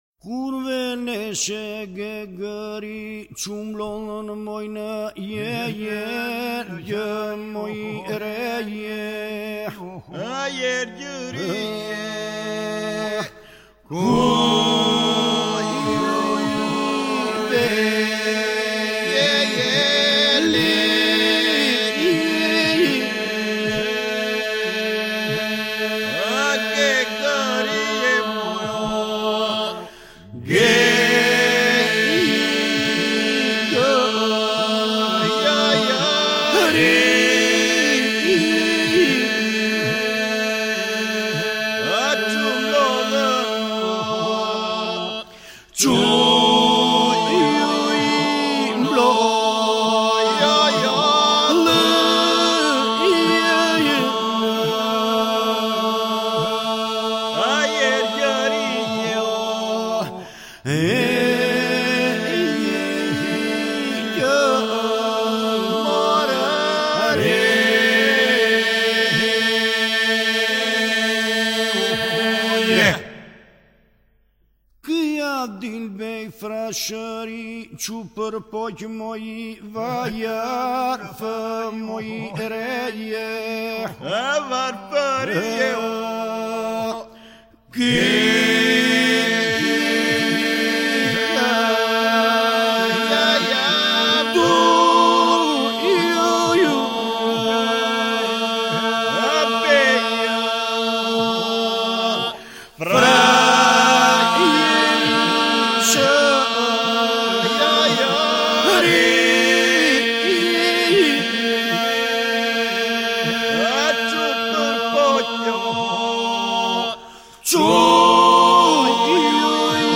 Grup më i shquar iso-polifonik i qytetit muze të Gjirokastrës, “Pasuri kulturore e njerëzimit mbrojtur nga Unesko”, i krijuar  në vitin 1976.
Database of Albanian Folk Iso-Polyphony